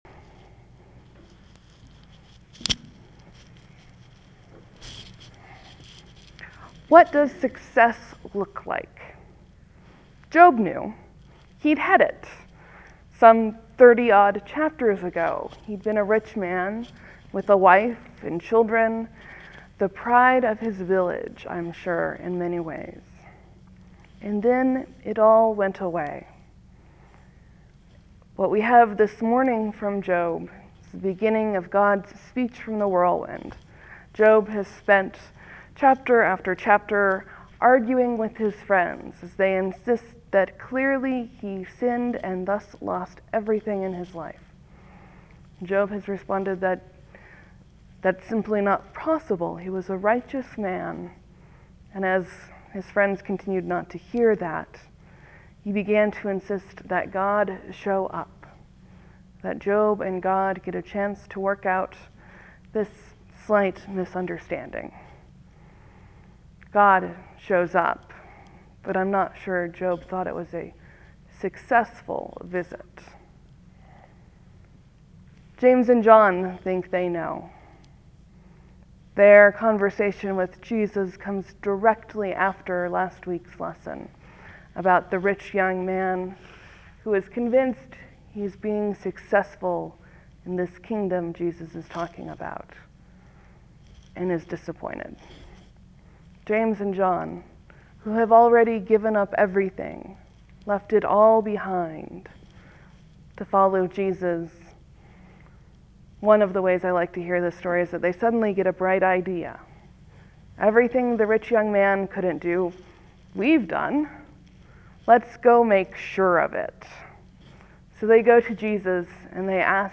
Sermon, , Leave a comment
(There will be a few moments of silence before the sermon begins. Thank you for your patience.)